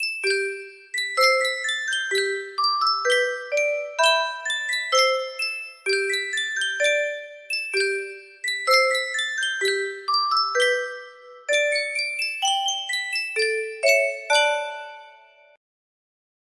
Sankyo Custom Tune Music Box - Jeanie With the Light Brown Hair music box melody
Full range 60